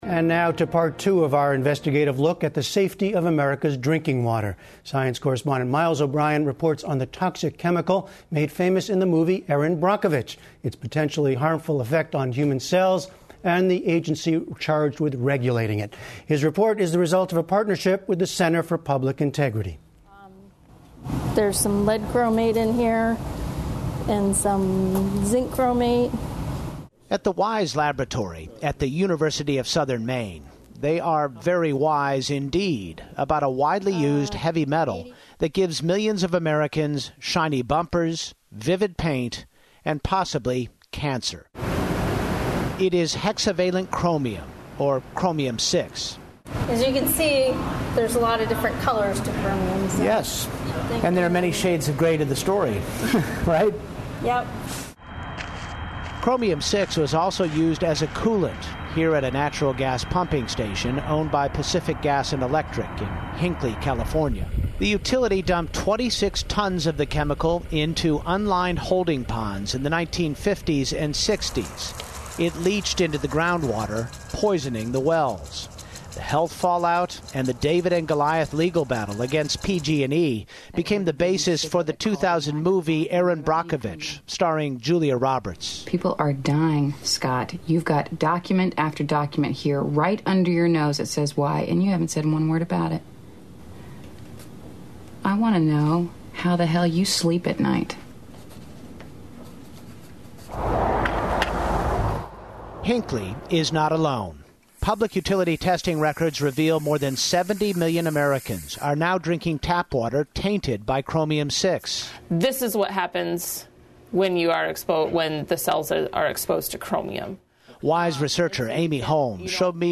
Science correspondent Miles O'Brien reports on the toxic chemical made famous in the movie "Erin Brockovich," its potentially harmful effect on human cells, and the agency charged with regulating it.